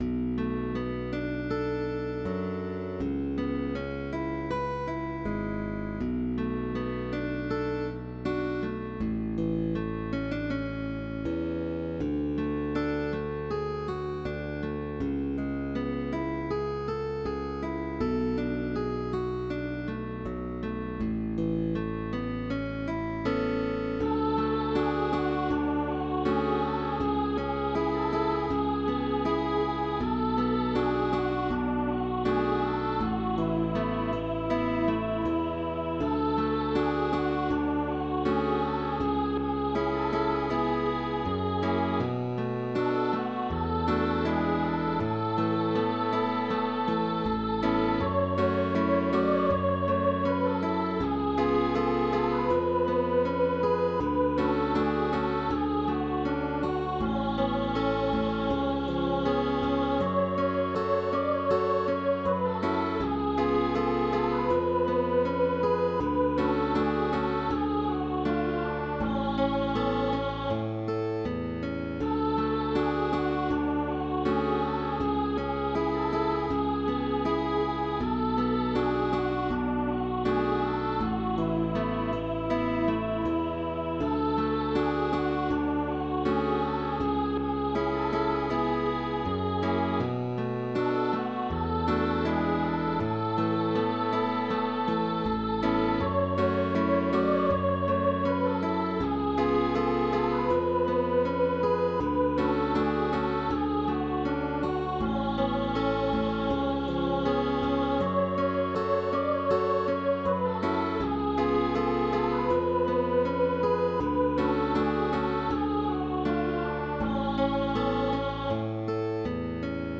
Partitura para voz y guitarra con bajo opcional.